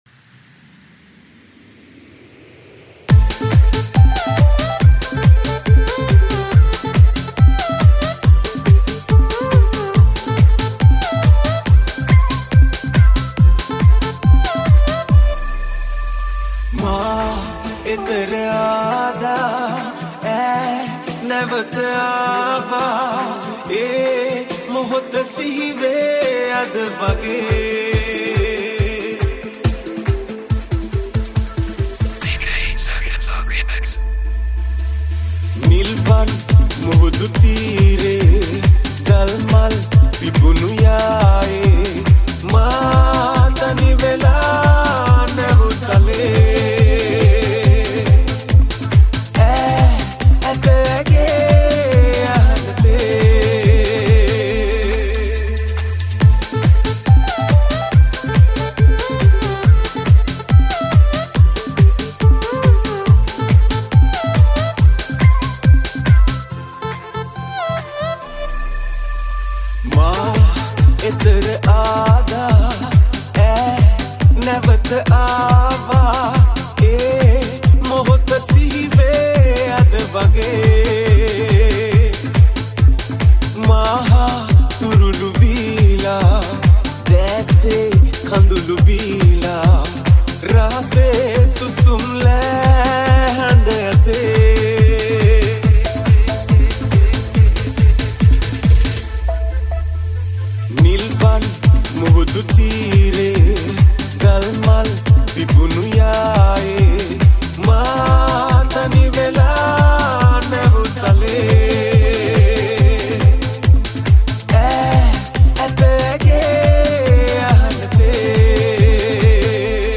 High quality Sri Lankan remix MP3 (3.8).
high quality remix